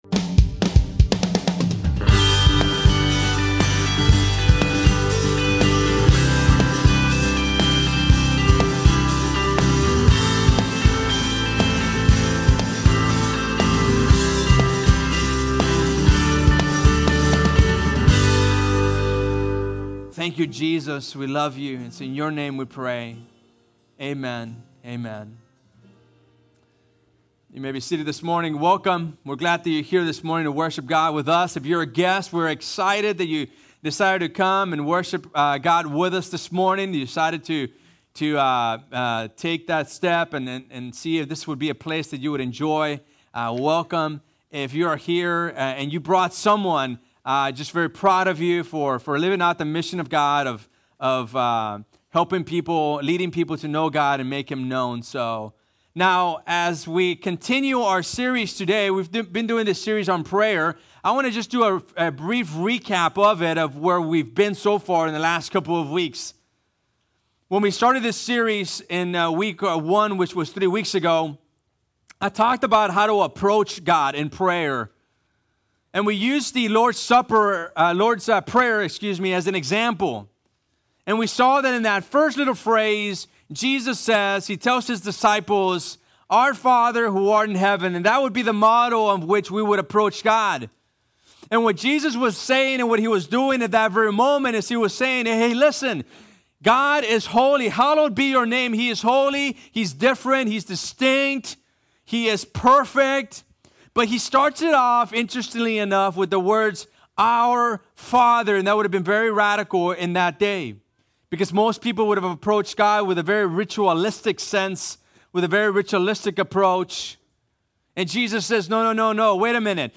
Two Rivers Bible Church - Sermons
Prayer - How to Receive an Answer From God 3 part sermon series on prayer: Everyone knows it's important but not everyone knows how to do it.